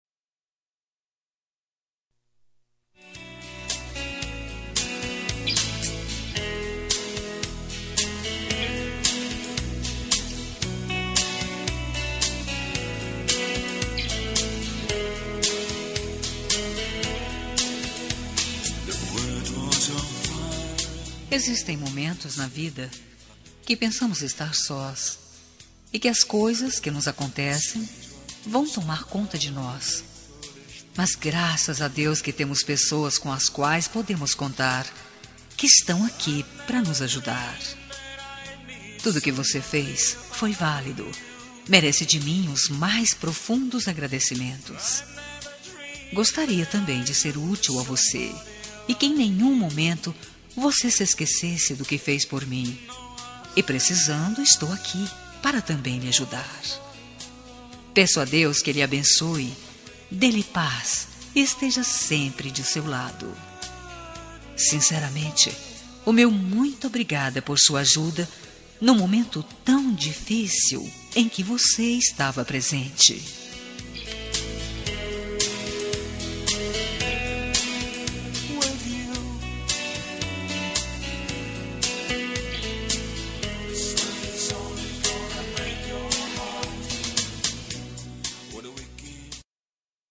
Telemensagem de Agradecimento – Pela Ajuda – Voz Feminina – Cód: 22